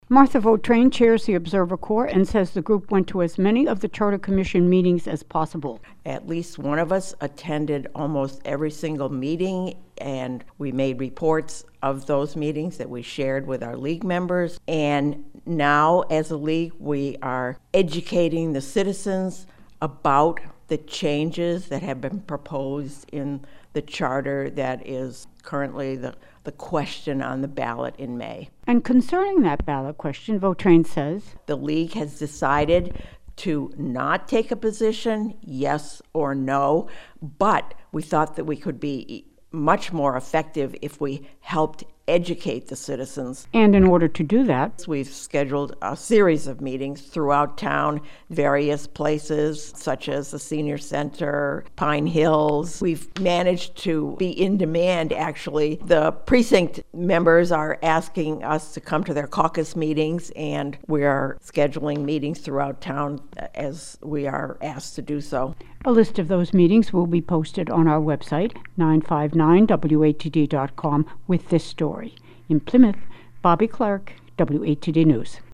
by | Mar 23, 2023 | News